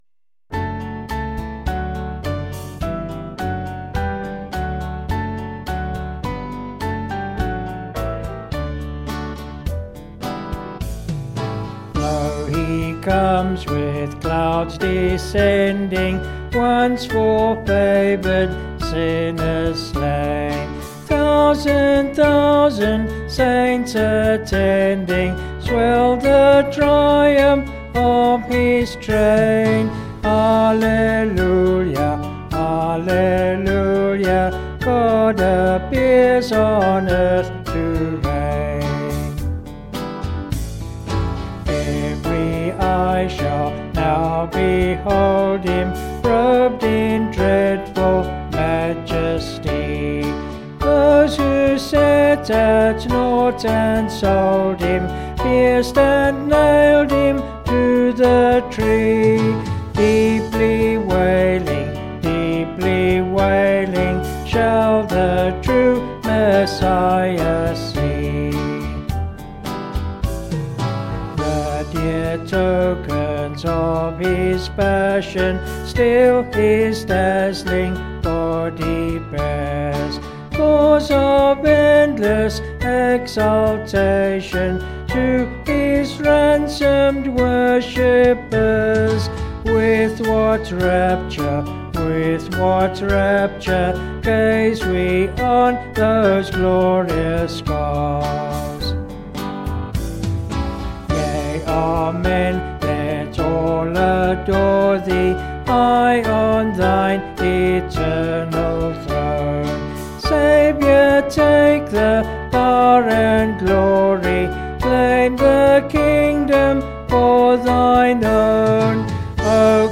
Vocals and Band   264.8kb Sung Lyrics